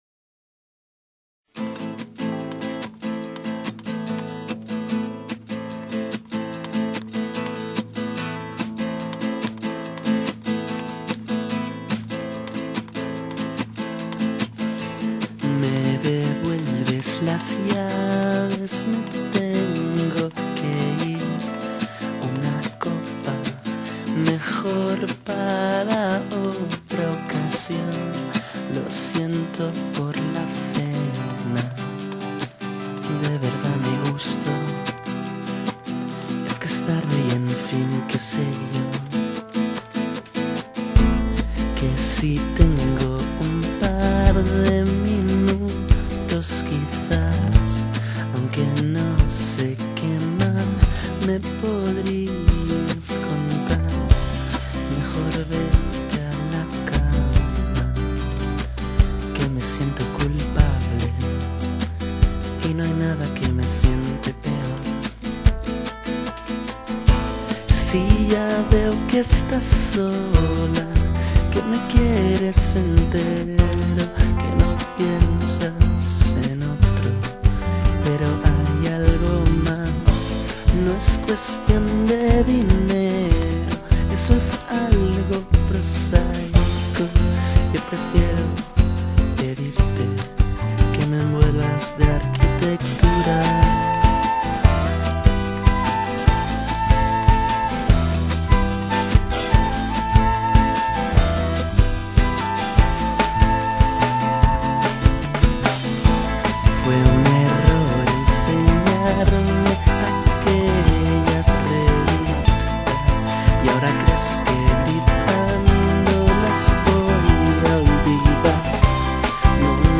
guitar
piano
drums
vocals and acoustic guitar